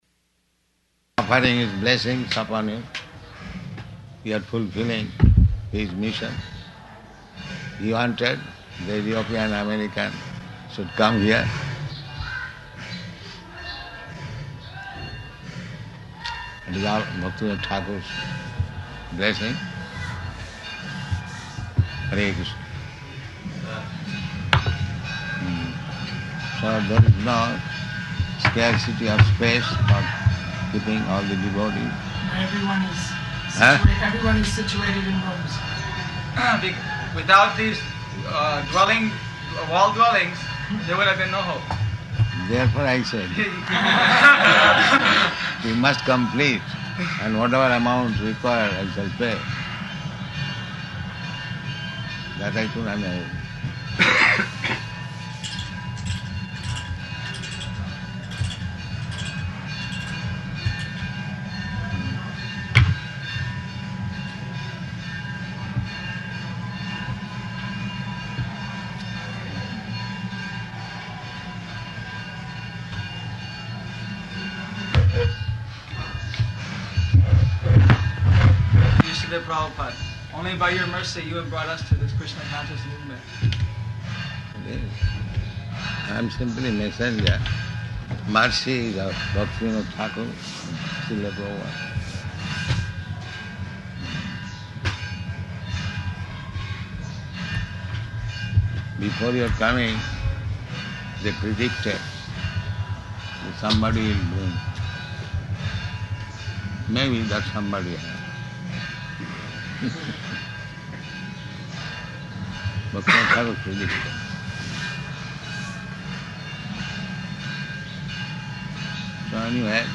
Arrival Talk in Room
Arrival Talk in Room --:-- --:-- Type: Lectures and Addresses Dated: March 23rd 1975 Location: Māyāpur Audio file: 750323AR.MAY.mp3 Prabhupāda: ...offering his blessings upon you.
[temple bells ringing in background]